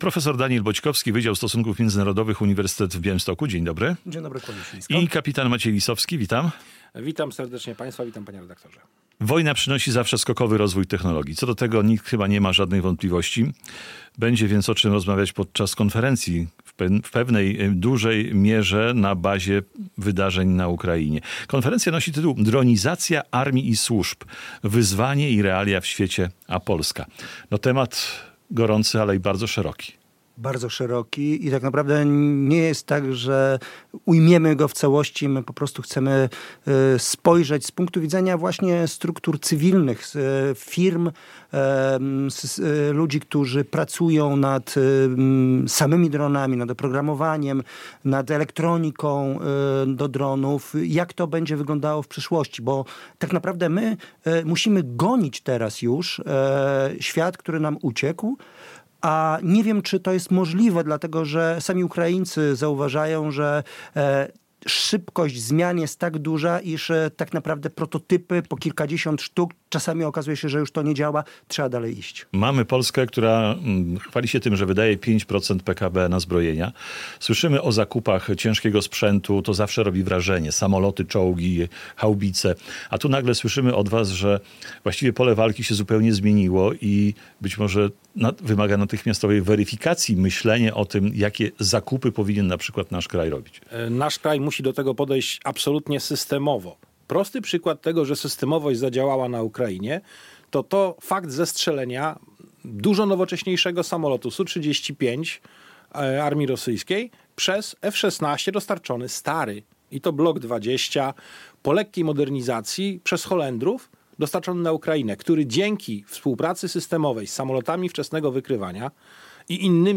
Gość